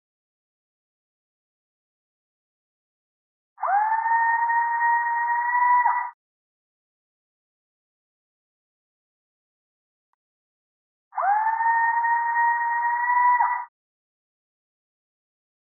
Sherburne_scream.mp3